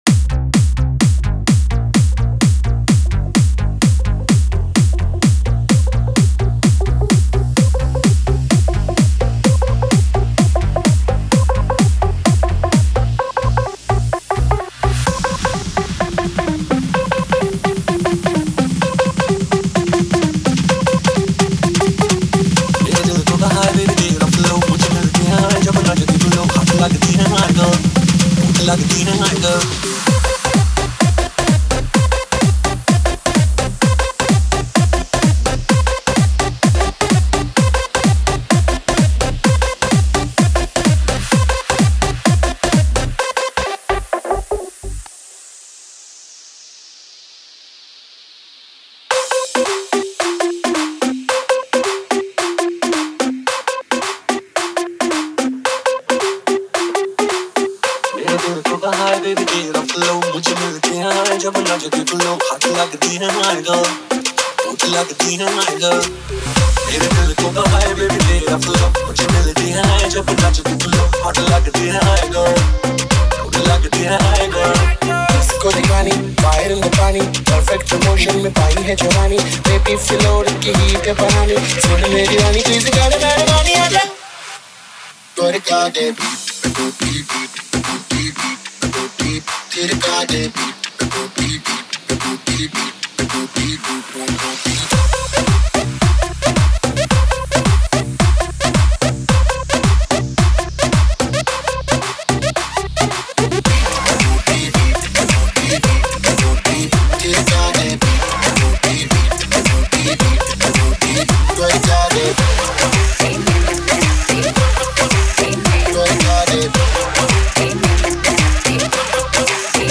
Bollywood DJ Remix Songs